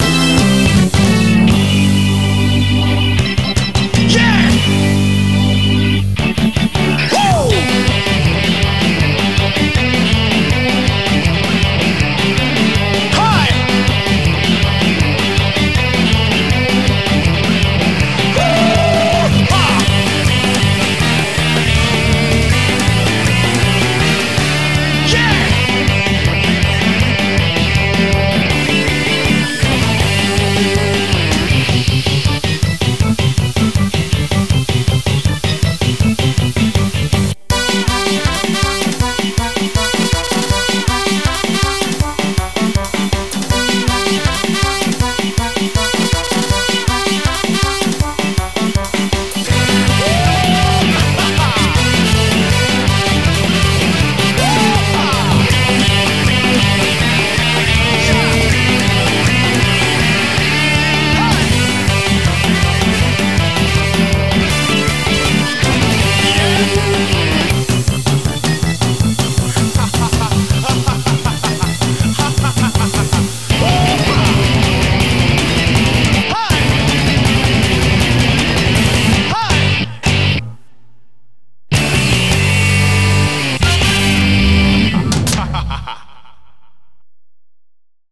BPM160
Audio QualityPerfect (High Quality)
Better quality audio.